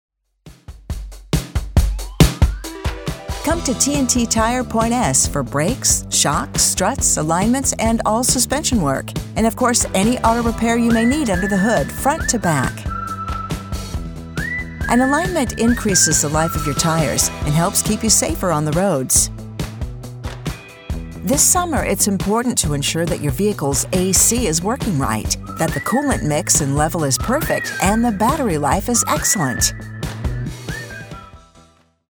New Music Every Quarter